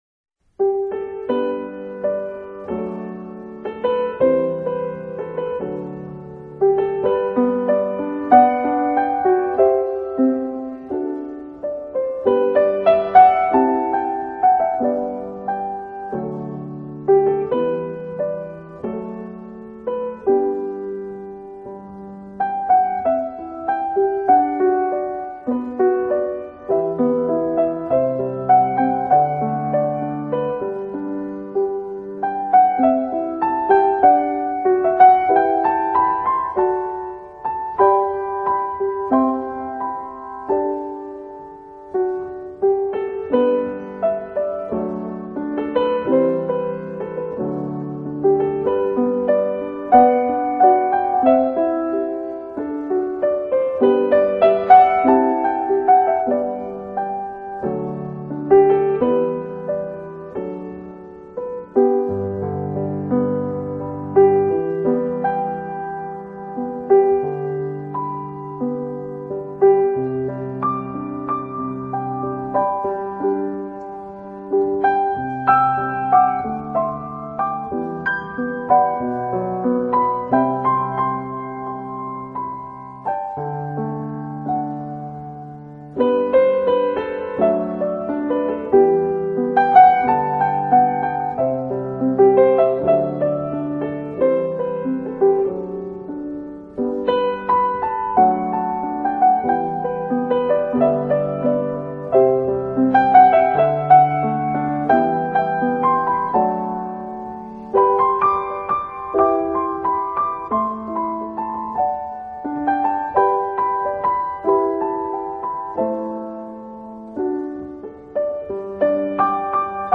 【新世纪音乐】
纯钢琴独奏
Age)旋律配合如流水行云的弹奏技巧，更添上几分柔情诗意，令乐迷耳目一新。